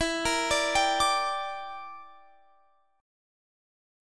Listen to Em7b5 strummed